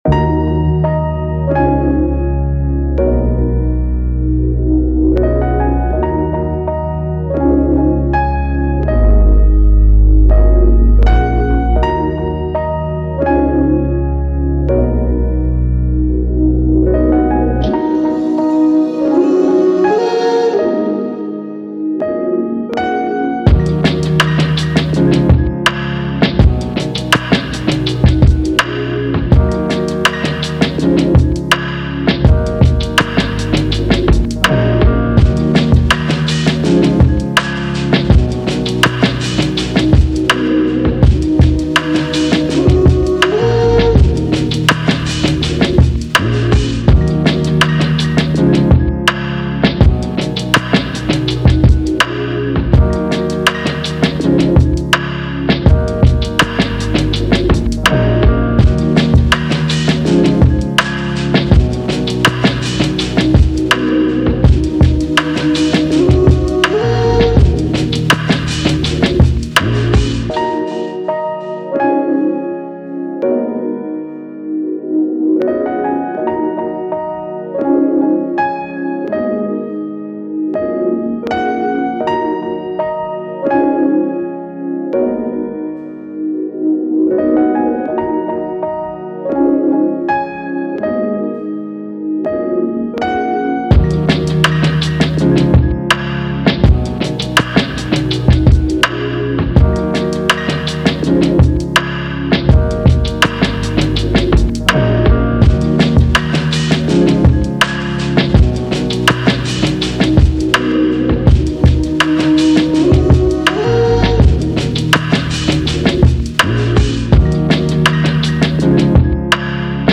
Hip Hop
Eb Min